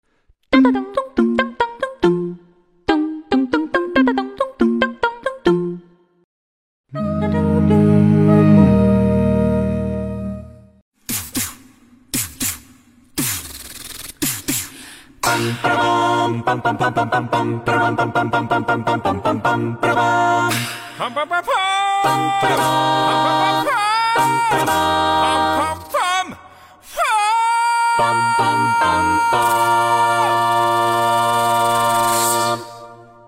These vocalists have recreated some sound effects free download By tech 280 Downloads 56 months ago 33 seconds tech Sound Effects About These vocalists have recreated some Mp3 Sound Effect These vocalists have recreated some iconic tech sound effects with just their voices!